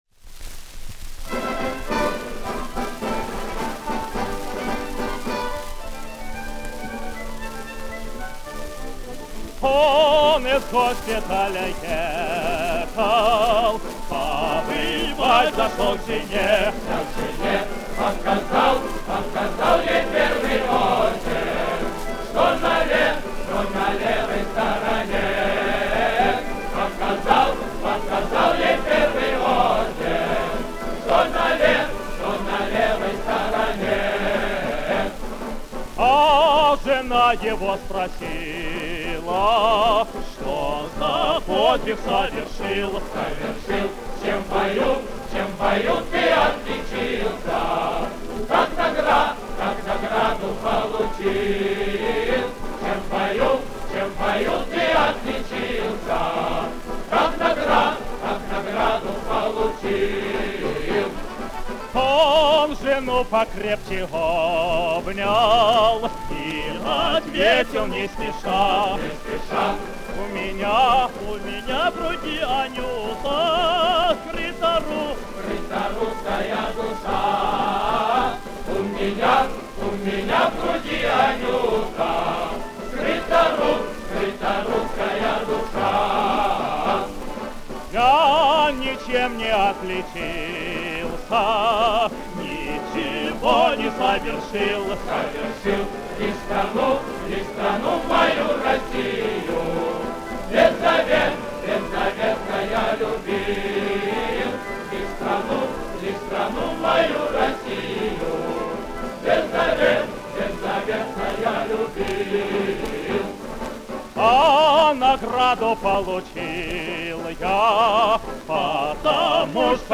Известная песня военных лет в превосходном исполнении.